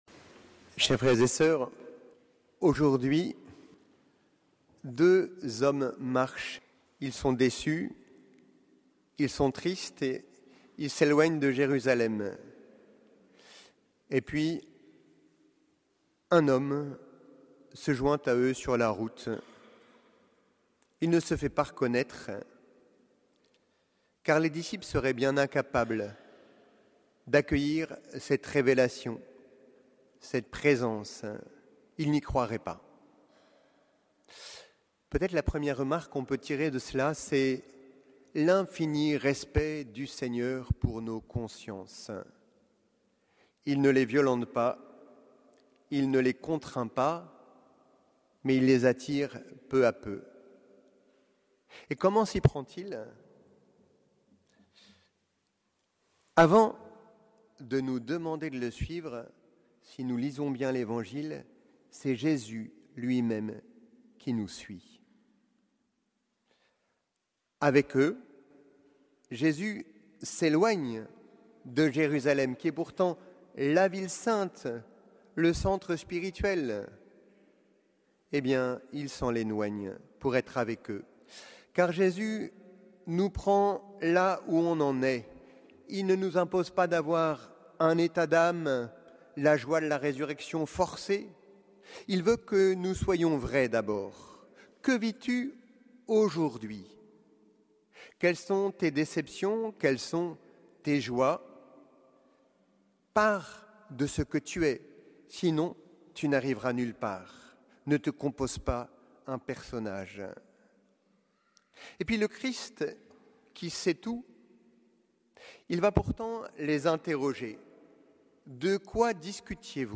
Homélie du troisième dimanche de Pâques